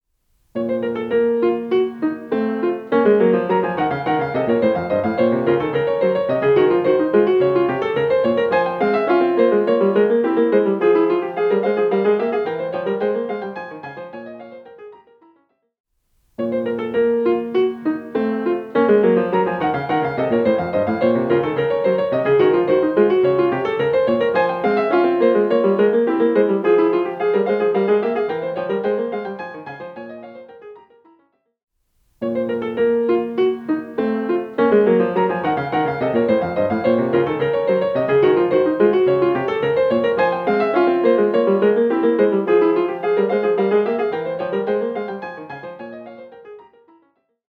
Old-Fashioned Music (1 of 3) • I try to be sensitive to the musical styles I play at Mass.
During the following hymn, I play two (2) organ interludes. The first uses a tiny smattering of counterpoint. When it comes to the second interlude, however, some feel its chromaticism sounds excessively “19th-century” or “saccharine” or schmaltzy.